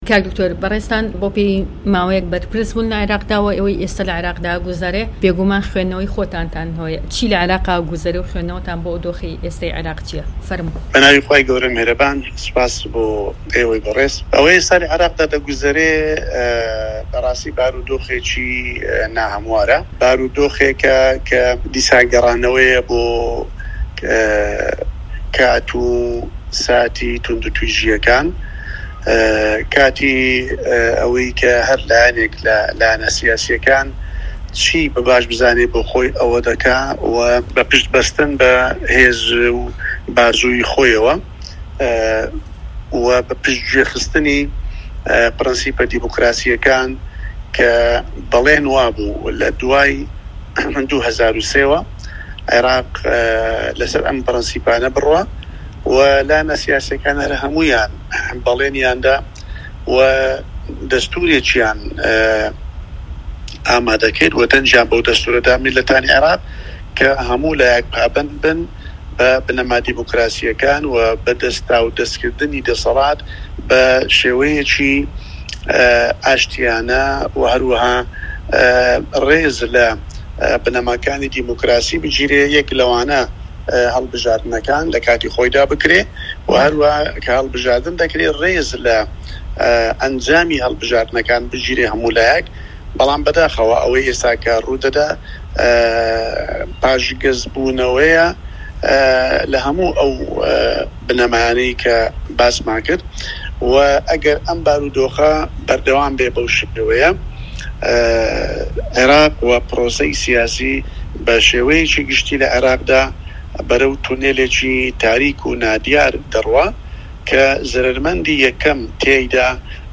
دەقی وتووێژەکەی دکتۆر بەشیر حەداد